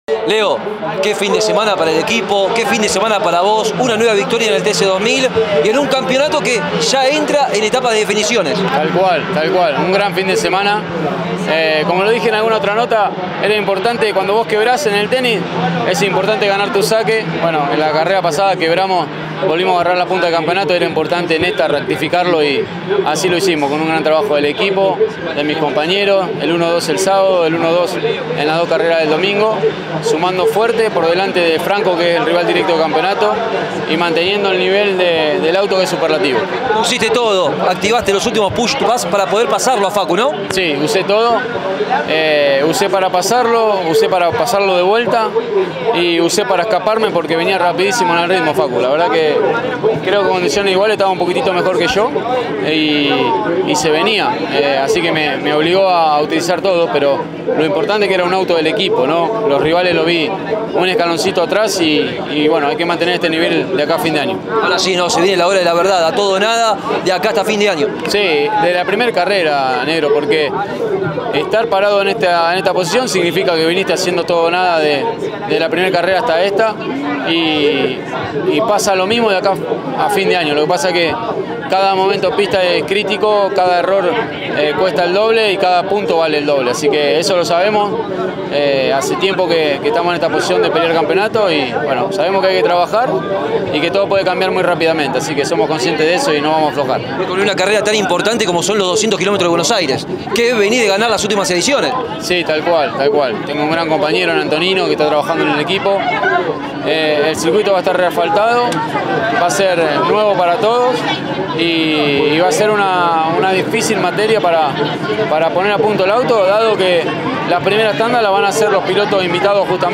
El campeón reinante pasó por los micrófonos de Pole Position y habló de la contundencia que tuvo el conjunto el fin de semana en La Rioja, que le permite ser como líder del campeonato.